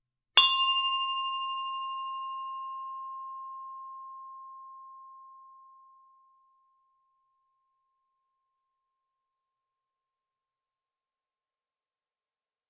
Bronze Bell 1
bell bronze ding percussion ring stereo xy sound effect free sound royalty free Sound Effects